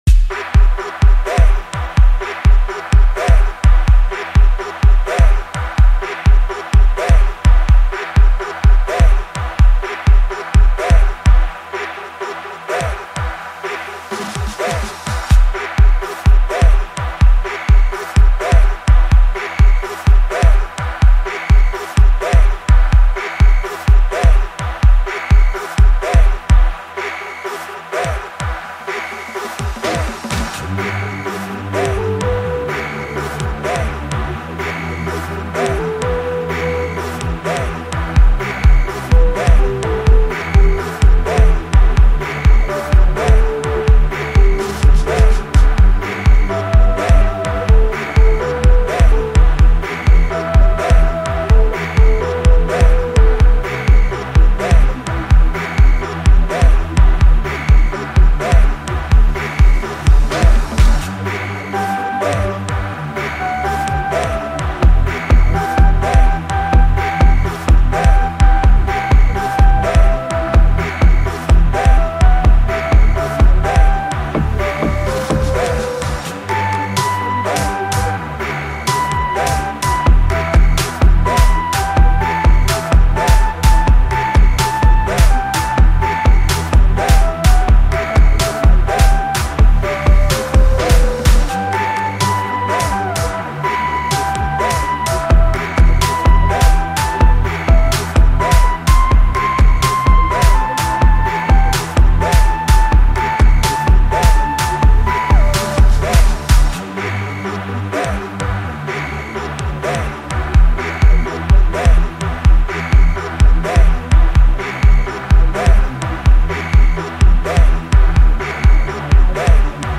Home » Gqom